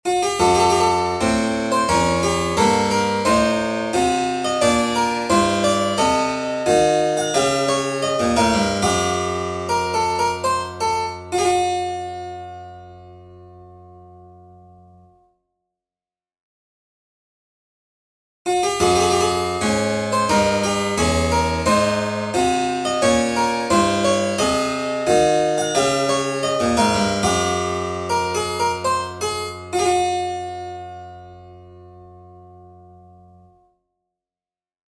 A Baroque-style oneirotonic keyboard exercise for a 13edo keyboard, one in Oneiromajor and one in Oneirominor. The two passages are meant to be played in all 13 keys.
Oneiro_Baroque_Exercises_13edo.mp3